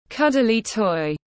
Thú nhồi bông lông mềm tiếng anh gọi là cuddly toy, phiên âm tiếng anh đọc là /ˌkʌd.əli ˈtɔɪ/